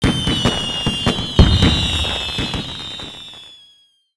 firecracker_xmas.wav